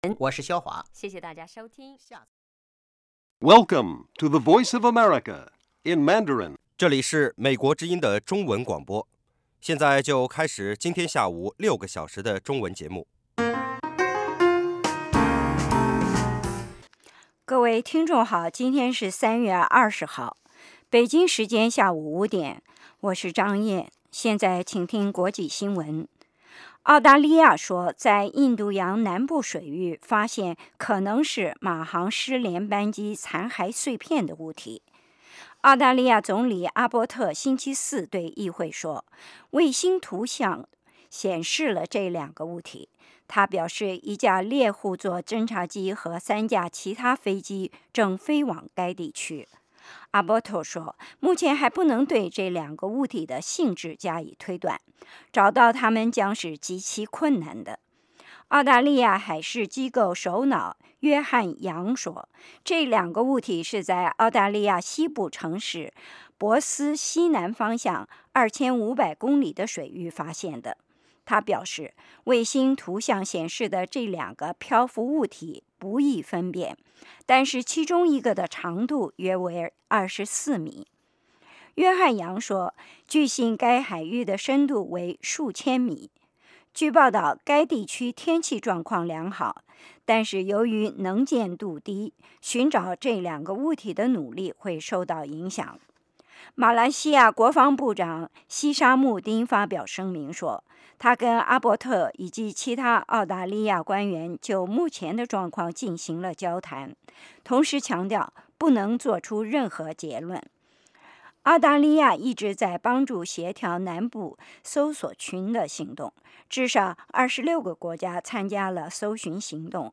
晚5-6点广播节目
国际新闻 英语教学 社论 北京时间: 下午5点 格林威治标准时间: 0900 节目长度 : 60 收听: mp3